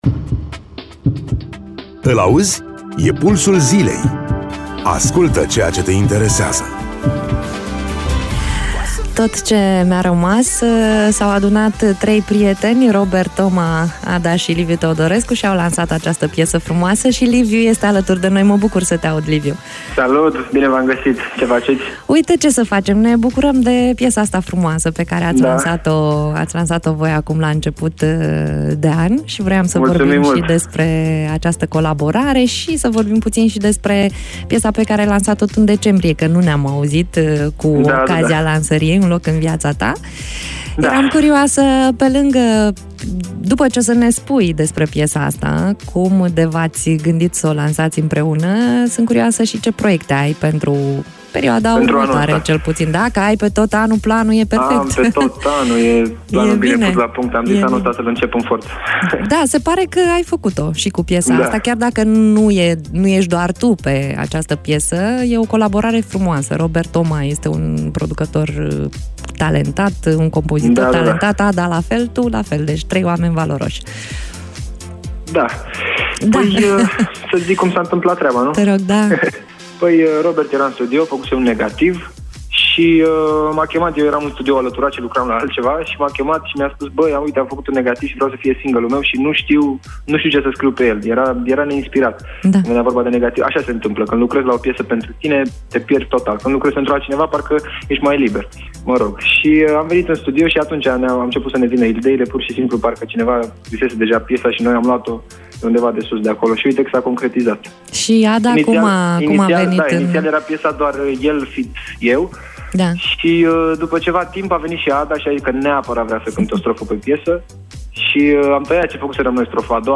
în direct la Radio Iaşi – Pulsul Zilei
interviul